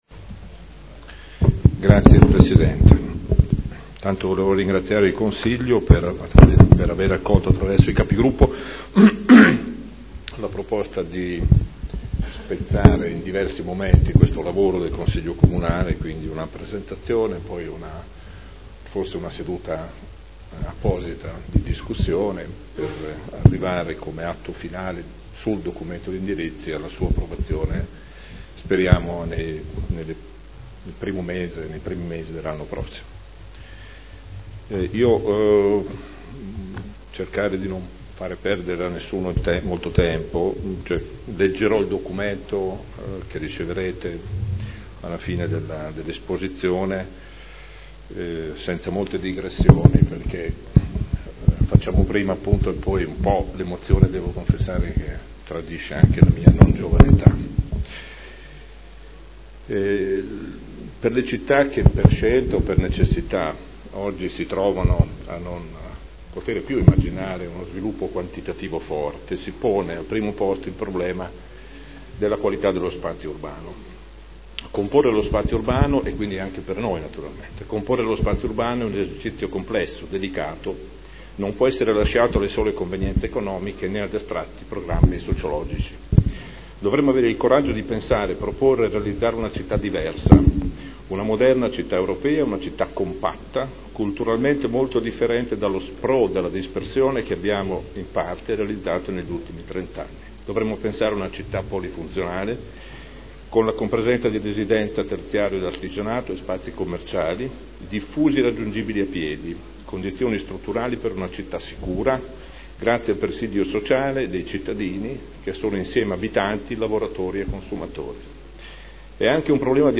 Seduta del 01/10/2012 Comunicazione sul PSC.